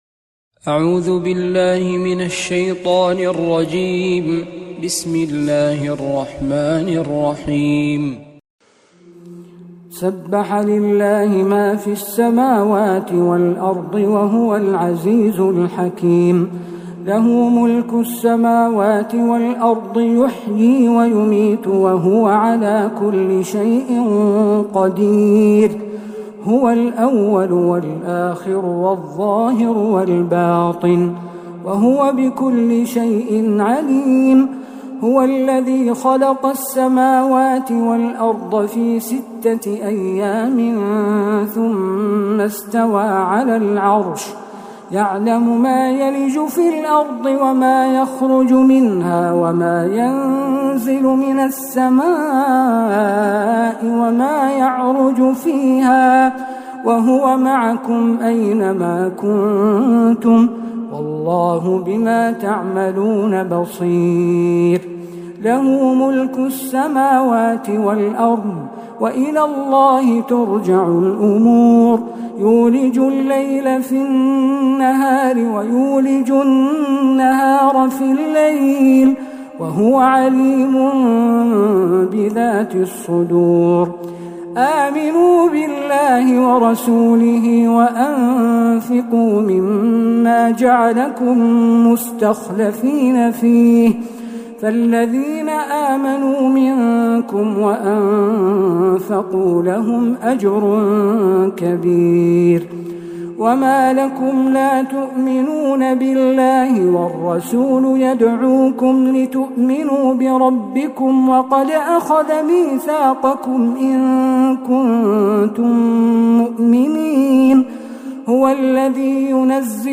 سورة الحديد كاملة من الحرم النبوي